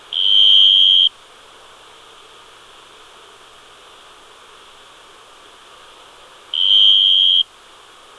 Males produce complex love songs by rubbing wings together.
Name : Slow-chirping Mottled Field Cricket - Lepidogryllus comparatus
Habits : male Field Cricket calling in the hole at night, heading outwards with antenna outside
FieldCricketSound13.wav